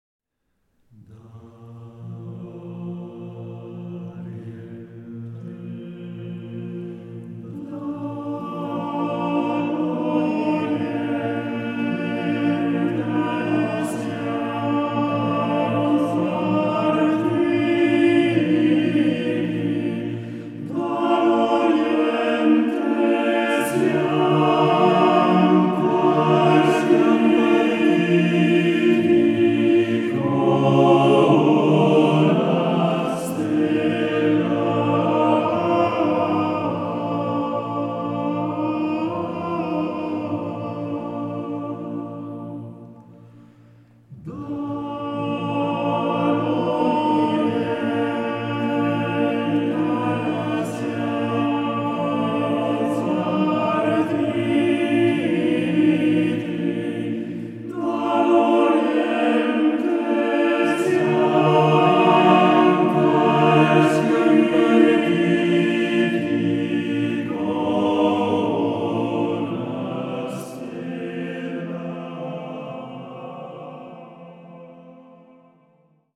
for male choir - italian folksong